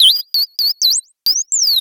Cri de Shaymin dans Pokémon X et Y.